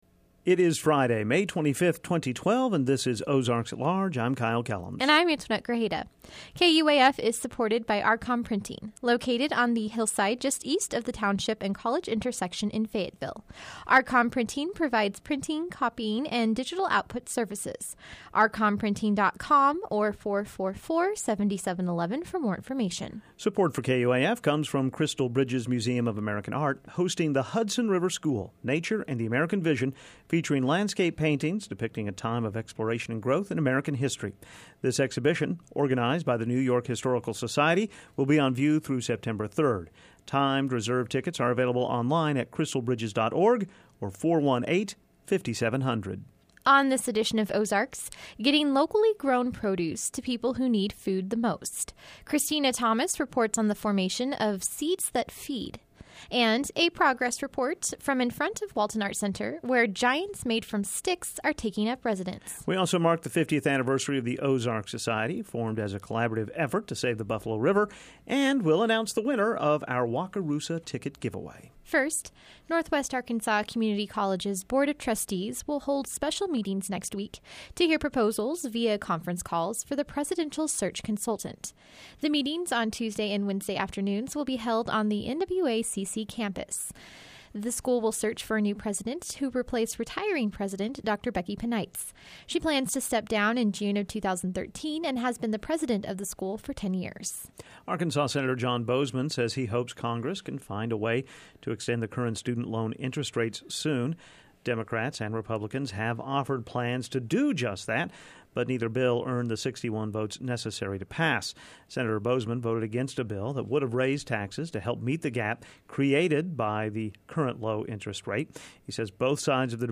Audio: 5-25-12.mp3 On this edition of Ozarks at Large, a conversation with “stick-work artist” Patrick Dougherty; and a new organization in Fayetteville hopes to do something different than the traditional canned food drive. Also on the show today, a preview of this year’s Artosphere Festival Orchestra.